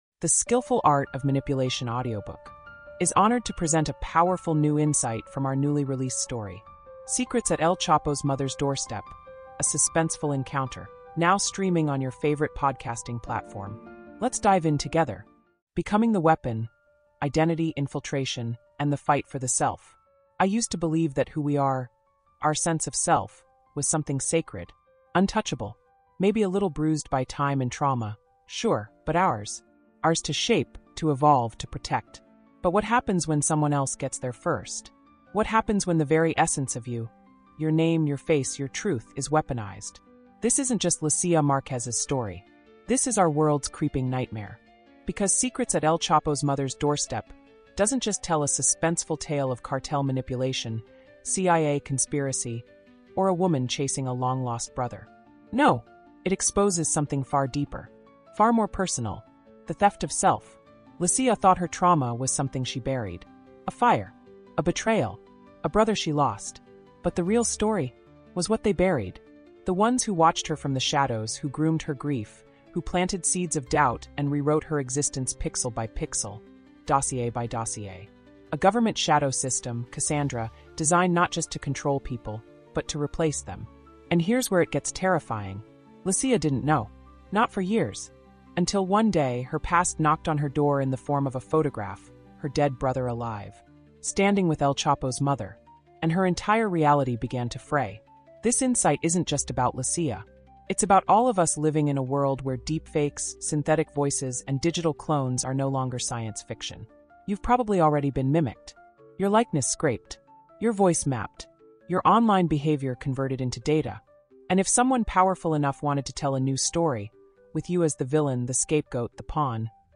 Through powerful narration and cinematic reflection, this insight asks: How do you hold onto your truth when the world no longer recognizes your face? Listeners will walk away questioning the nature of control, loyalty, trauma, and the algorithms that shape us.